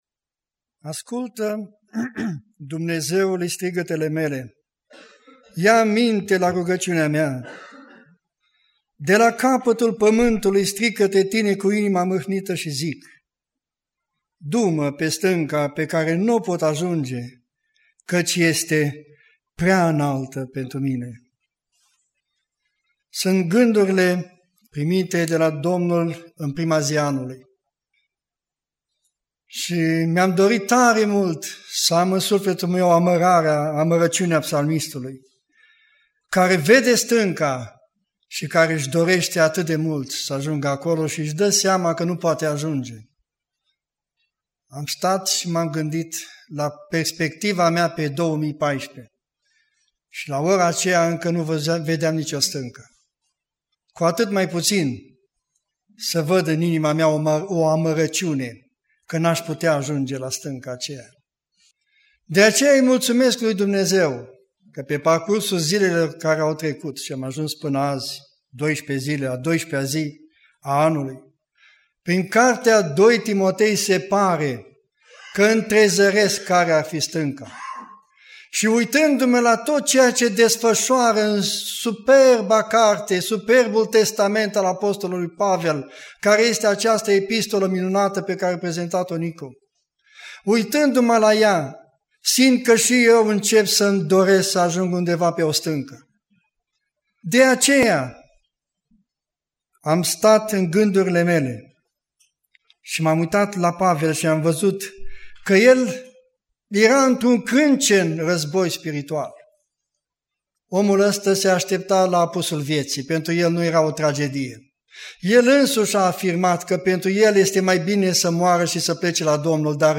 Predica Aplicatie 2 Timotei Introducere